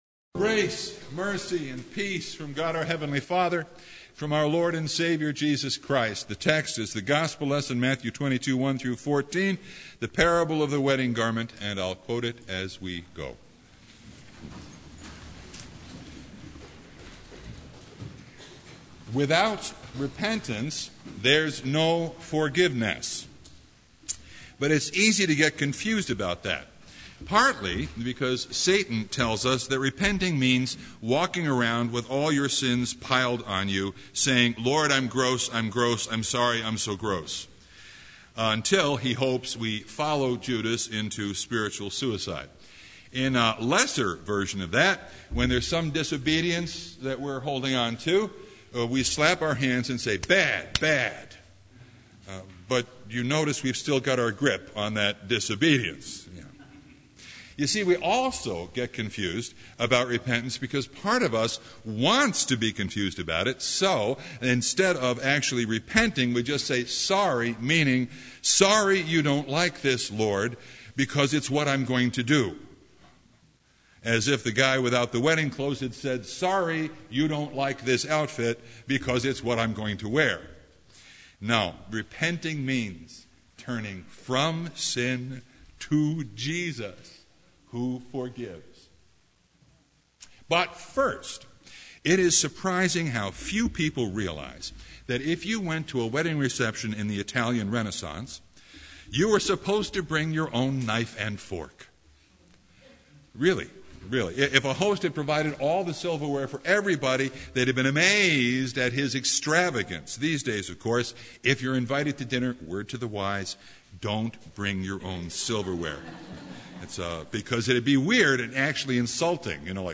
Passage: Matthew 22:1-14 Service Type: Sunday
Sermon Only